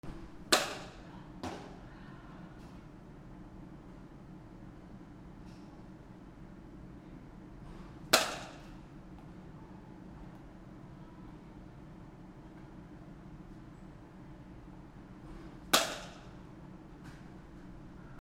野球1 ボールを打つ
/ M｜他分類 / L25 ｜スポーツ